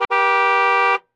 honk1.ogg